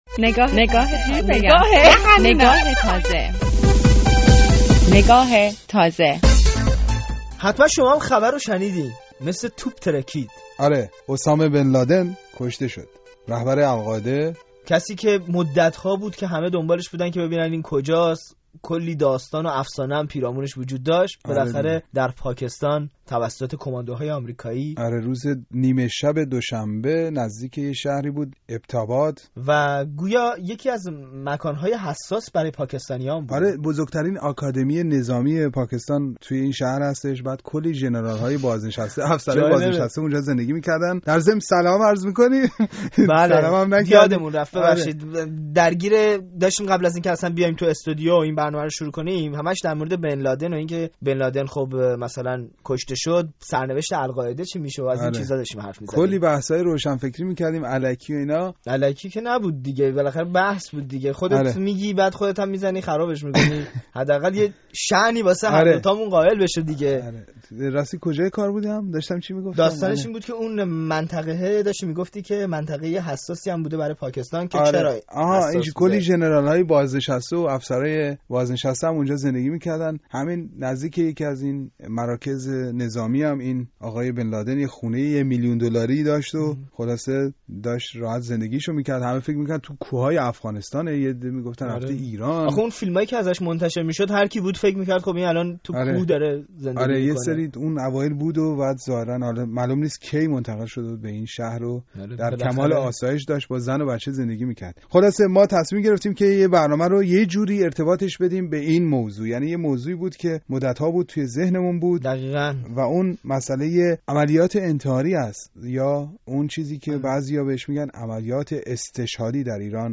نگاه تازه: بررسی دلایل حمله انتحاری در گفت‌وگو با دو کارشناس